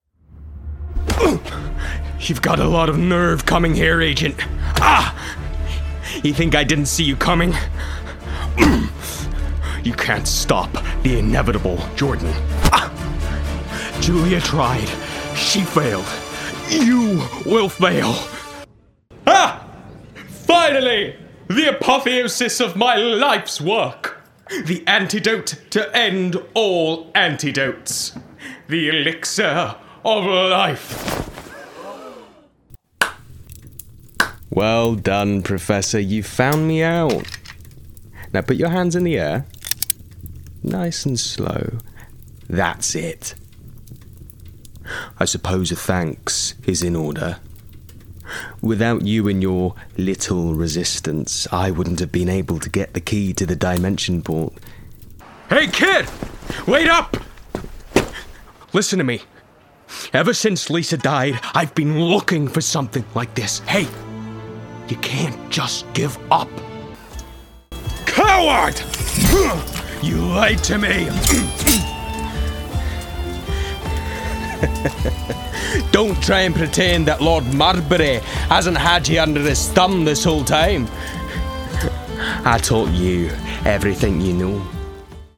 20s-30s. Deep tone. A fresh, contemporary voice with an amusing edge. Home Studio.
Computer Games